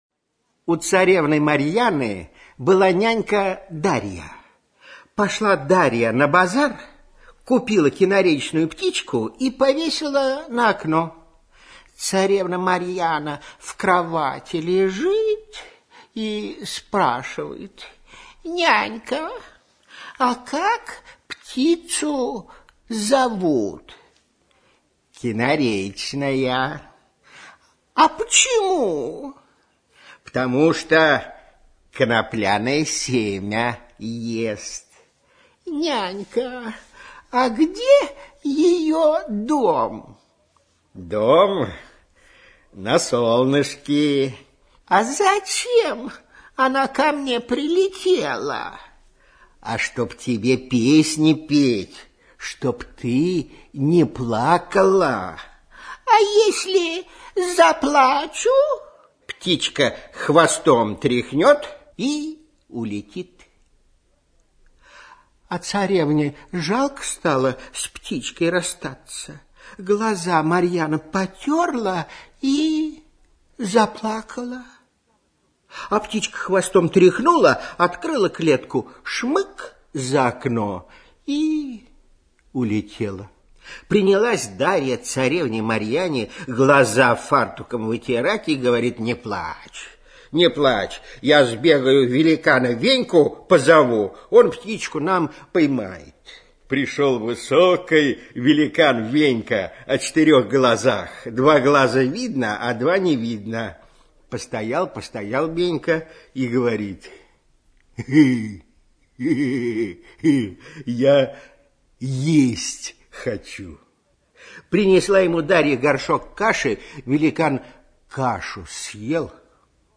На протяжении многих лет Литвинов являлся популярнейшим ведущим отечественного радиовещания - его мягкая и обаятельная манера исполнения всегда отличалась точностью интонаций во время чтения сказок и других литературных произведений.